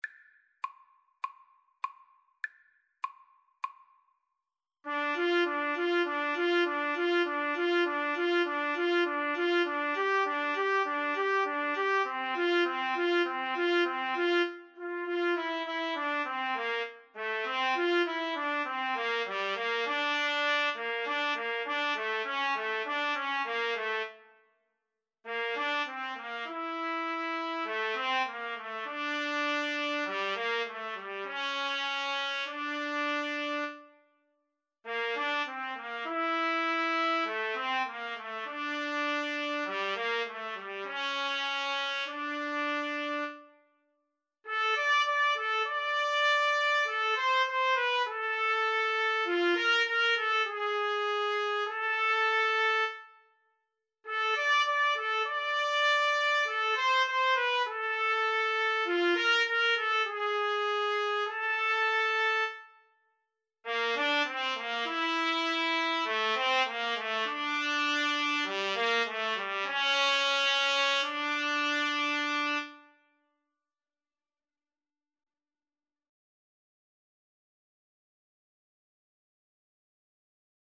Play (or use space bar on your keyboard) Pause Music Playalong - Player 1 Accompaniment reset tempo print settings full screen
D minor (Sounding Pitch) E minor (Trumpet in Bb) (View more D minor Music for Trumpet Duet )
4/4 (View more 4/4 Music)
Moderato
Traditional (View more Traditional Trumpet Duet Music)